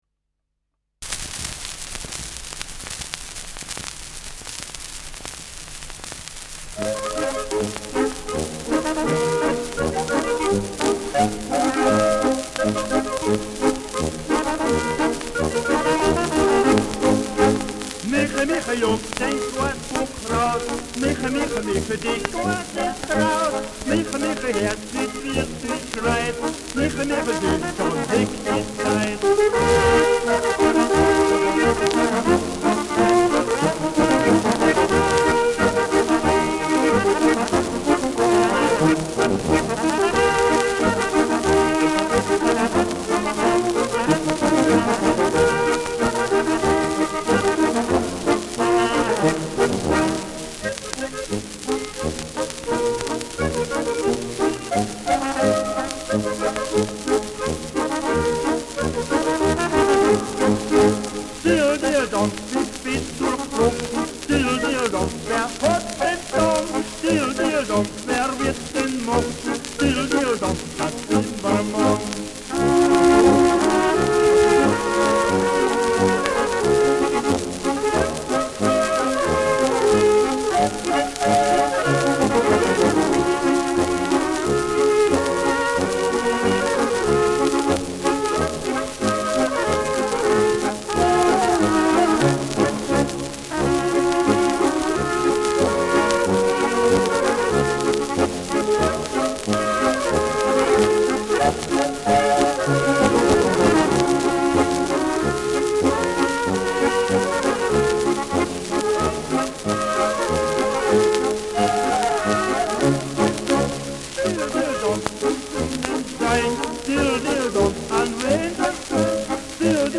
Schellackplatte
Tonrille: graue Rillen : Kratzer 12 / 5 Uhr
präsentes Rauschen
Mit Gesang.